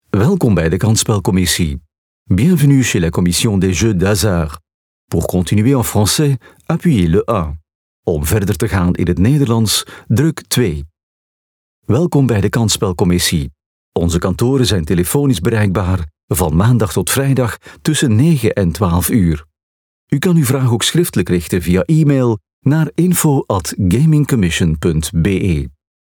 Comercial, Profundo, Seguro, Cálida, Empresarial
Telefonía